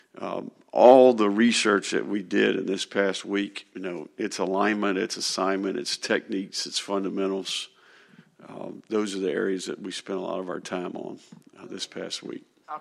The Gators had intense practices during the bye week in preparation for Saturday’s home game against UCF, Florida football coach Billy Napier said during Monday’s news conference.